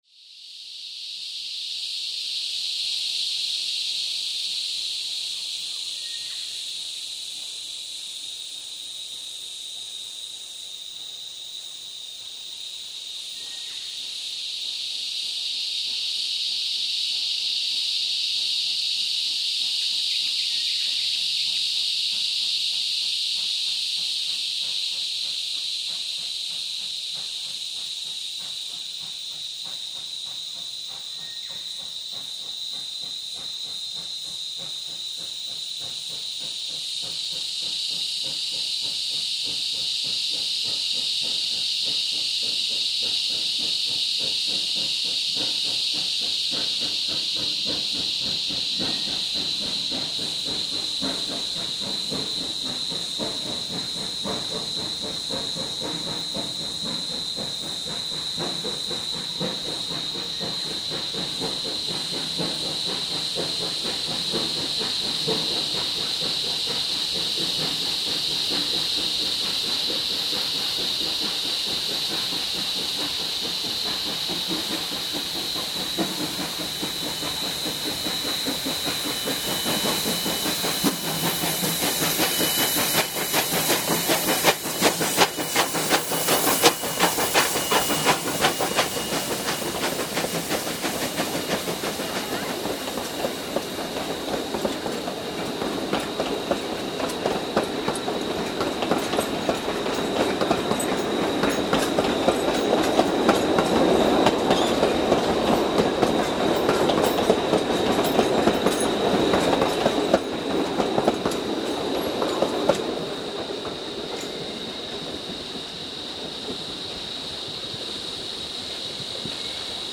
By this time, the sun had come out a little, and as you can hear, the Cicadas are rejoicing.  They were so loud in fact that they nearly drowned out the sound of the train, but 802 eventually wins over before the cacophony of cicadas again takes hold.  While   it is distracting from the sound of the train, this is a very typical Australian bush sound, especially during our long, hot summers.